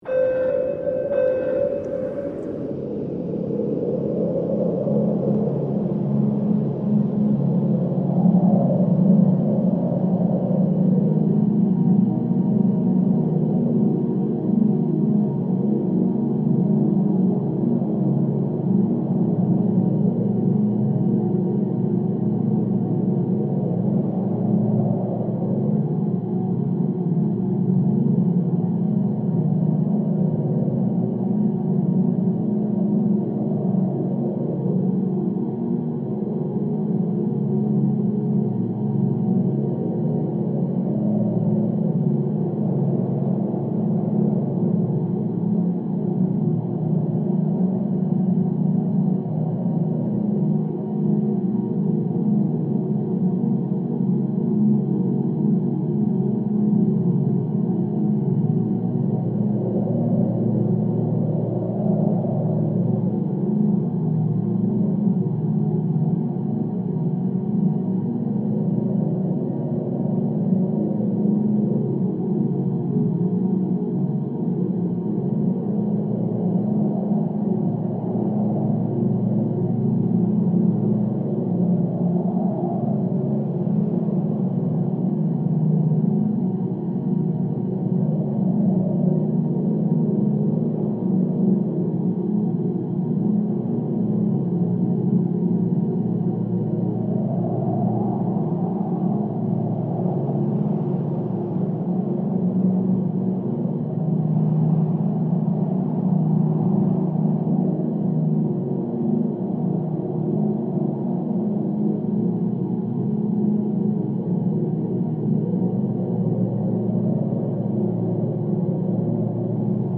3 hour scp ambient with blizzard sounds relaxing music (1).mp3
3-hour-scp-ambient-with-blizzard-sounds-relaxing-music-1.mp3